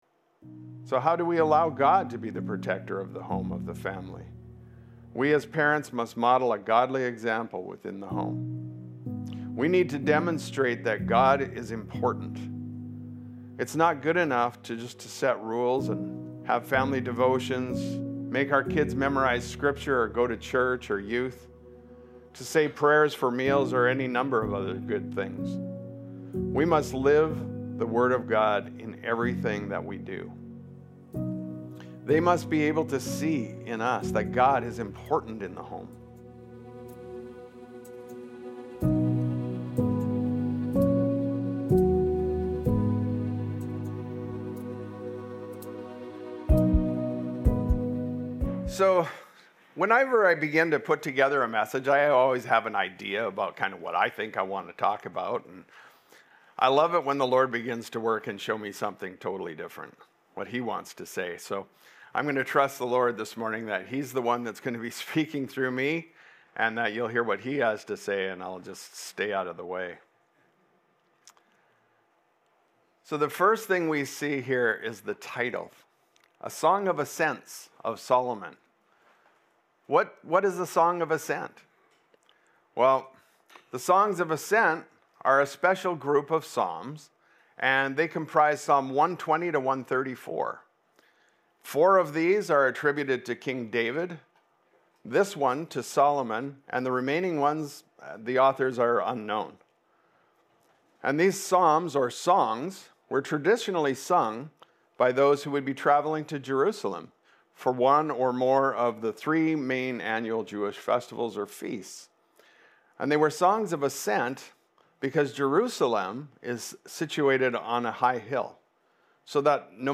done on Love Life Sunday